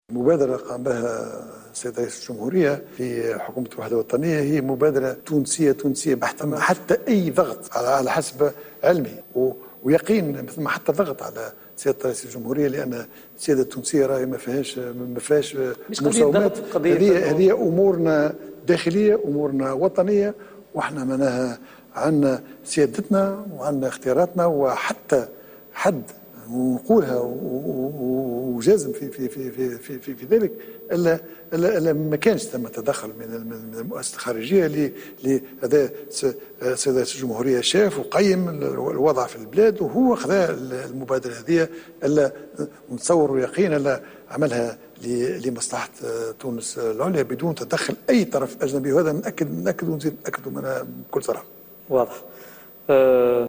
وقال الصيد في حوار خاص على قناة التاسعة إن مبادرة رئيس الجمهورية هي مسألة داخلية وأقدم رئيس الجمهورية على اتخاذها بعد معاينته للأوضاع مؤكدا أن تونس لها سيادتها وفق تعبيره.